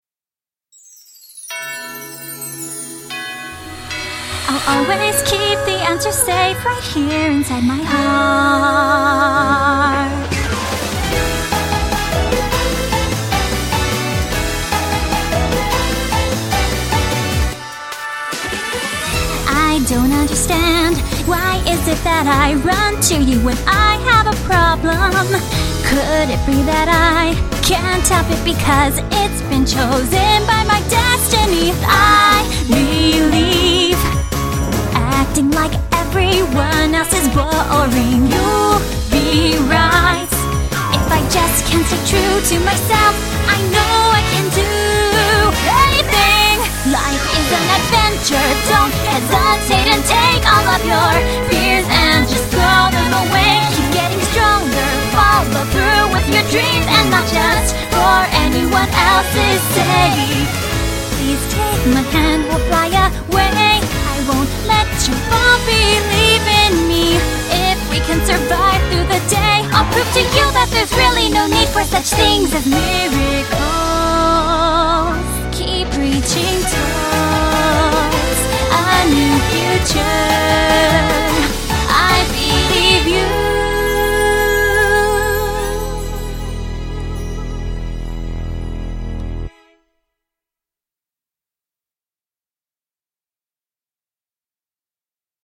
BPM75-150
Audio QualityCut From Video